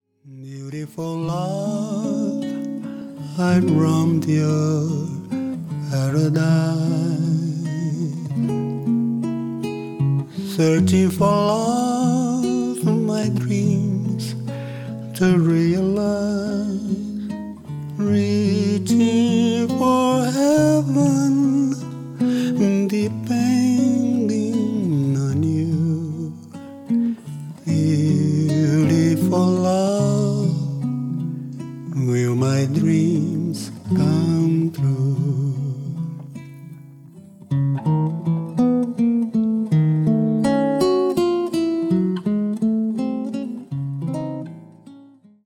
ギターと声だけ。
まろやかなその歌は円熟の極み。ギター弾き語りでジャズやブラジル音楽のスタンダードを歌う。
vo,g